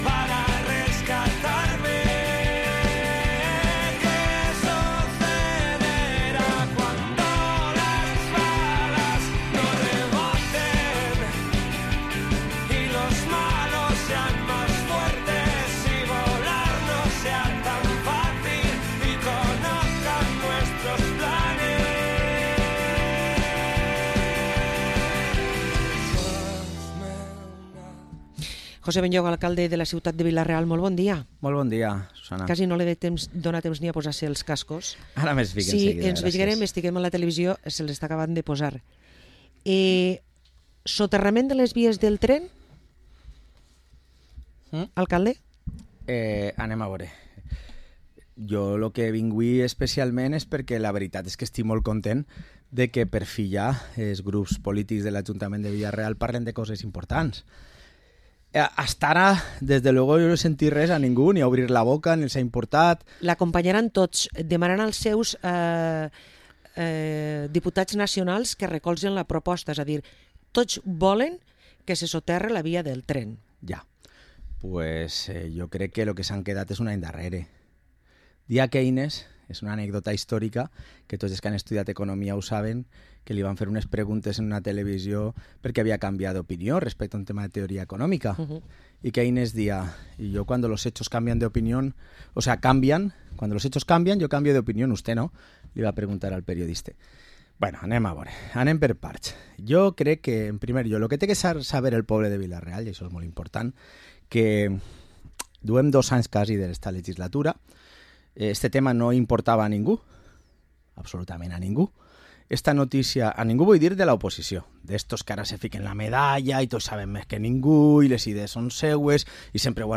Parlem amb l´Alcalde de Vila-real, José Benlloch